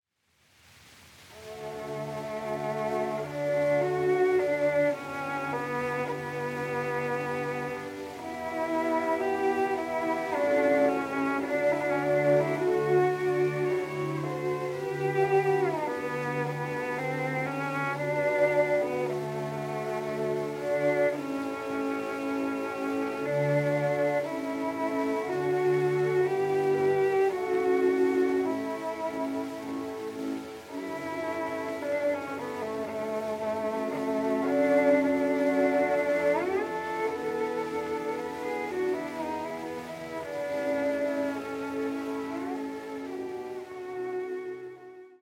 古い録音で聴くチェロの響きの奥深さよ。
録音：1916〜20年　モノラル録音